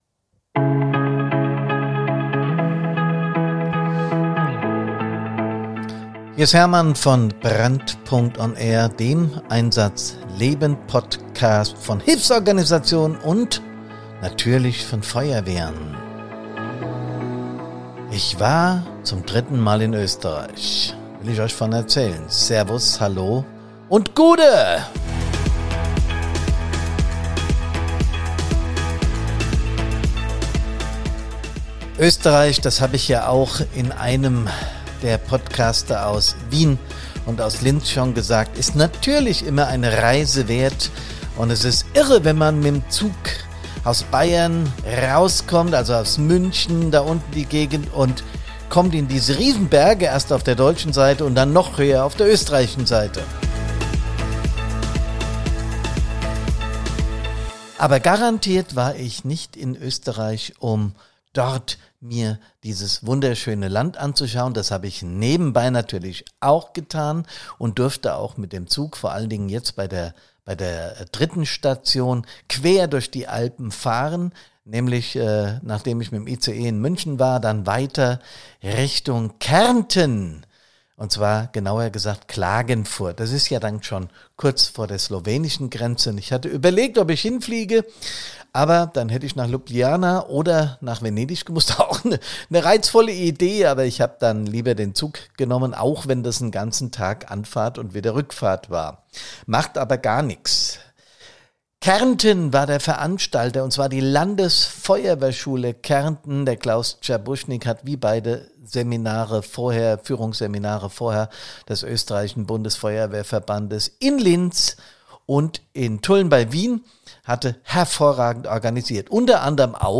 Interviewgäste